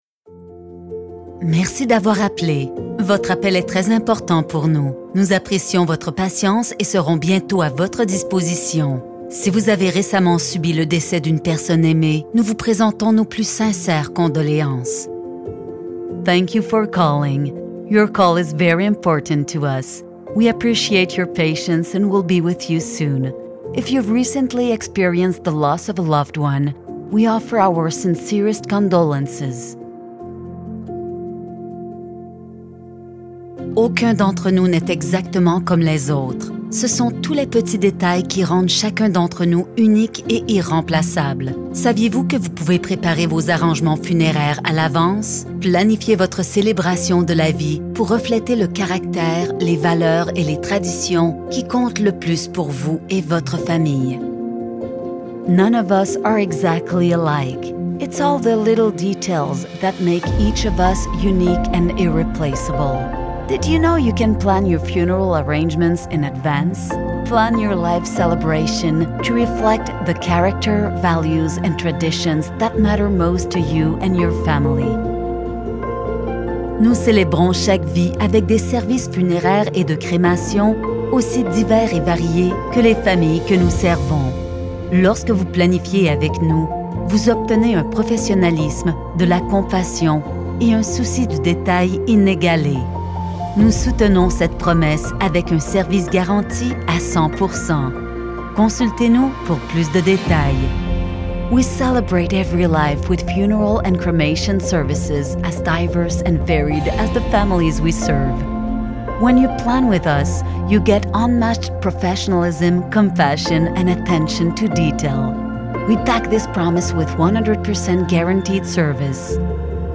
Dignity Memorial On-Hold Message
dignite-on-hold-standard-french-english